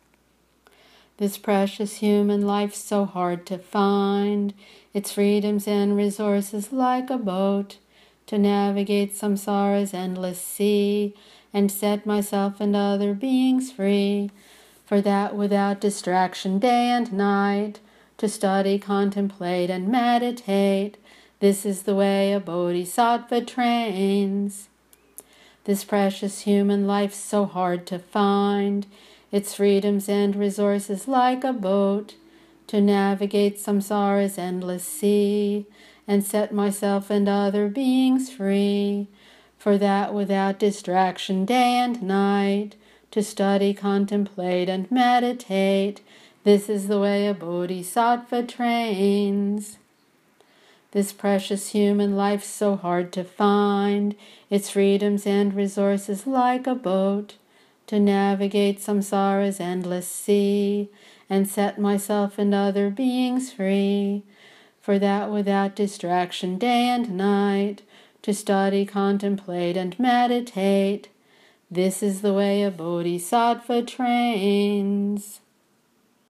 Verse 1 chanted 3x.